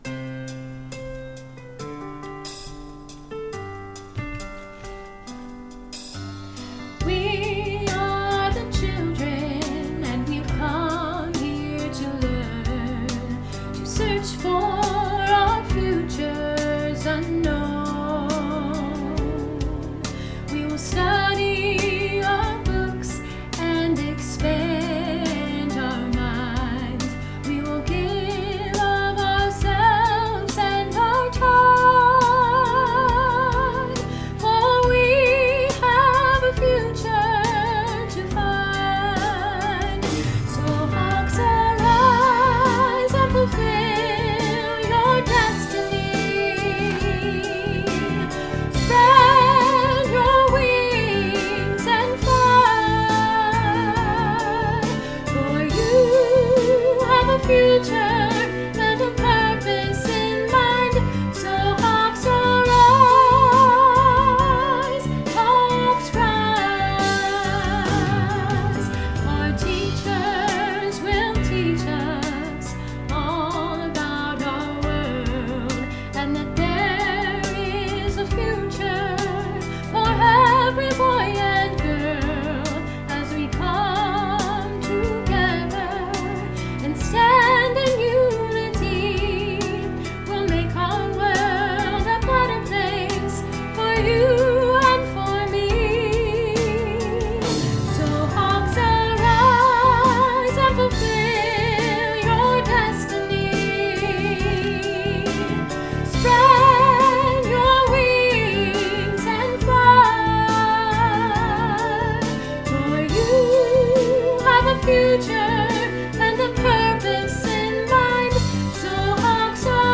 5th grade honor chorus (Graduation)